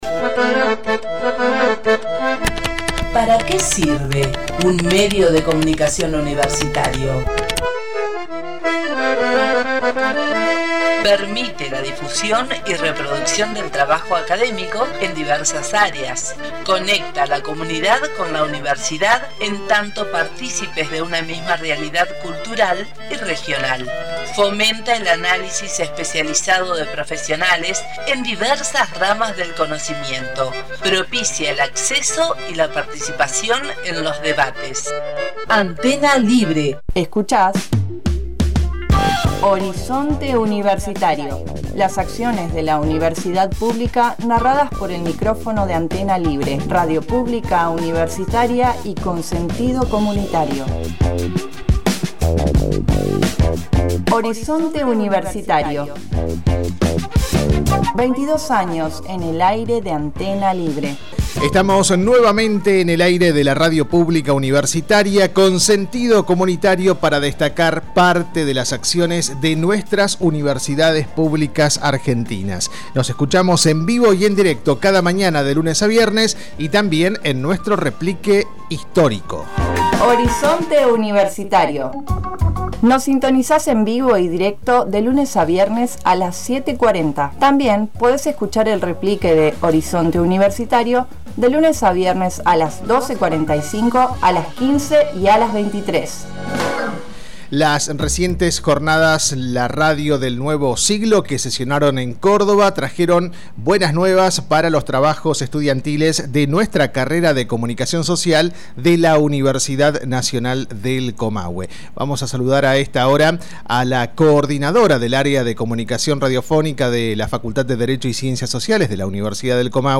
dialogamos